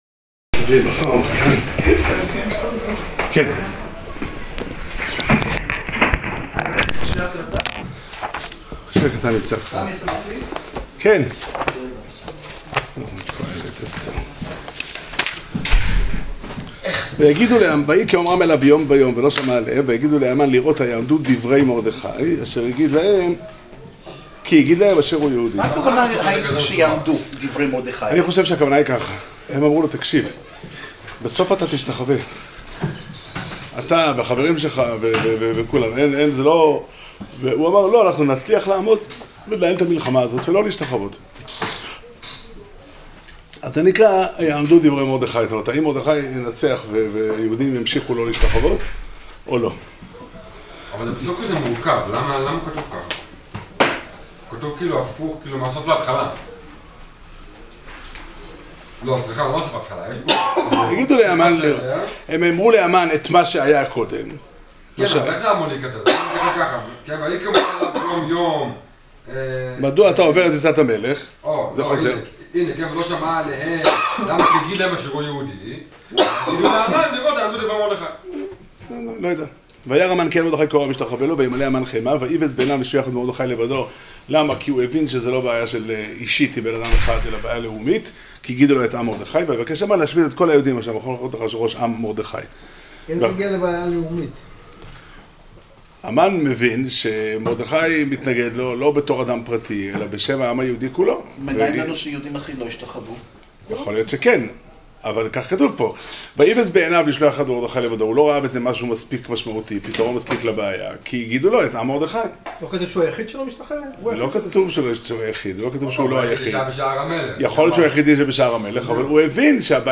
שיעור שנמסר בבית המדרש פתחי עולם בתאריך ד' אדר ב' תשע"ט